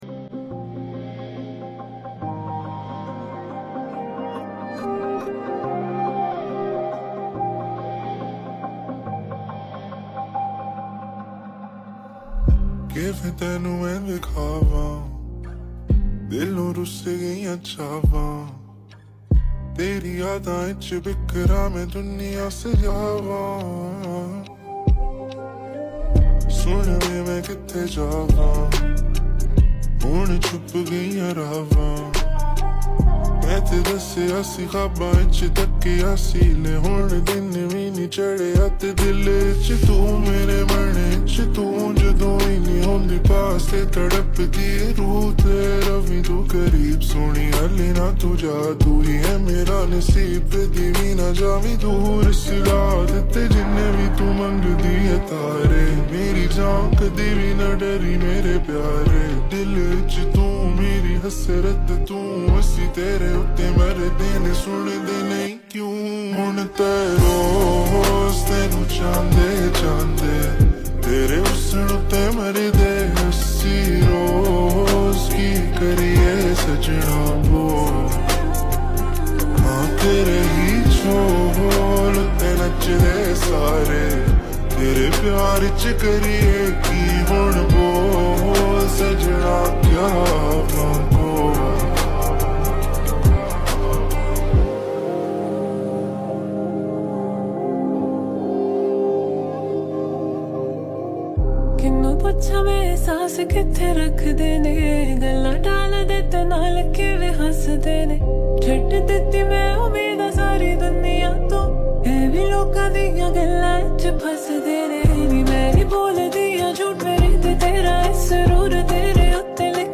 Slowed and Reverbed || Wear headphones for better Experience